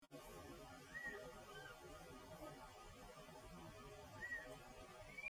Large-headed Flatbill (Ramphotrigon megacephalum)
Life Stage: Adult
Province / Department: Misiones
Location or protected area: Reserva Privada y Ecolodge Surucuá
Condition: Wild
Certainty: Recorded vocal